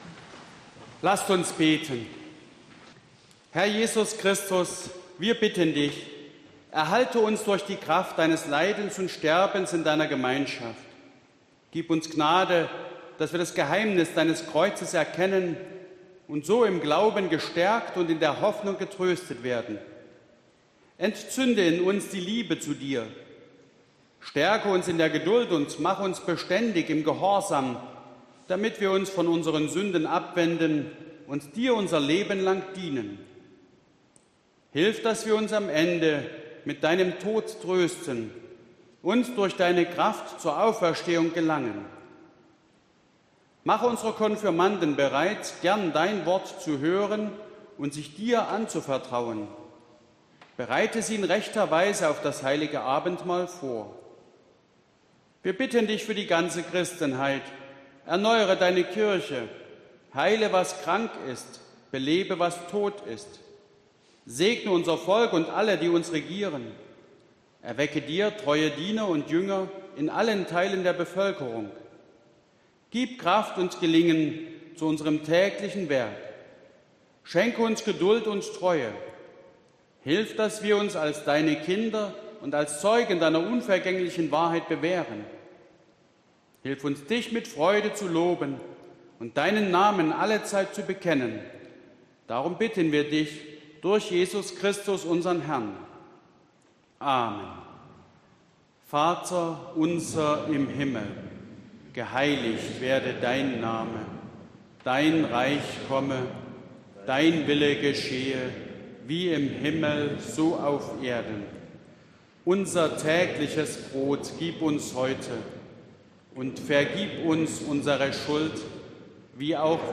Gottesdienst am 28.03.2021